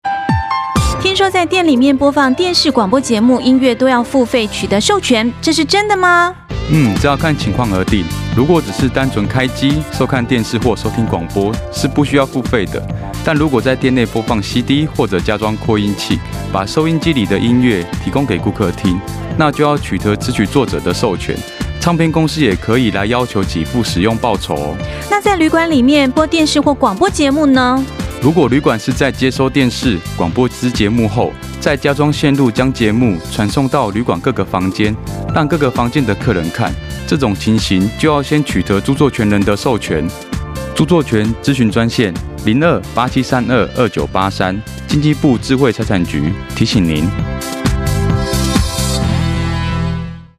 （二）運用全國各廣播電台播放保護著作權宣導廣告及「營業場所著作權篇」50秒宣導短劇等共計1,260檔次以上，全面建立民眾保護智慧財產權之正確認知。